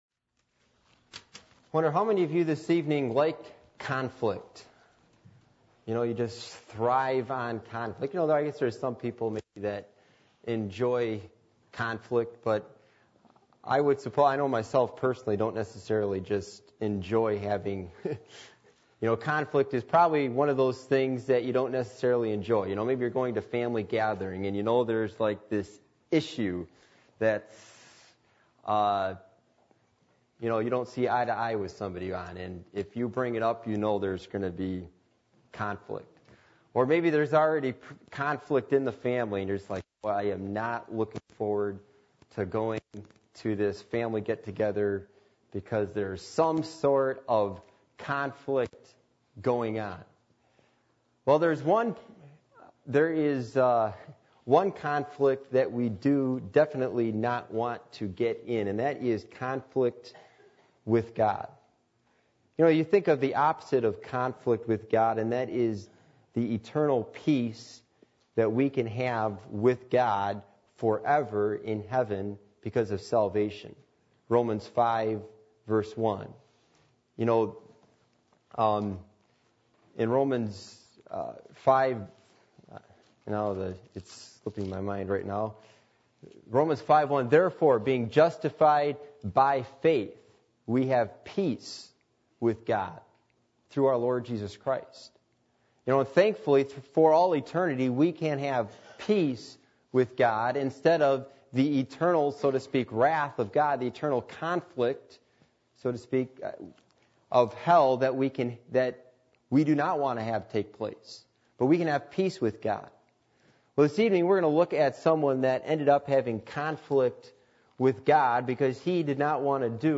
Passage: Jonah 1:1-10 Service Type: Sunday Evening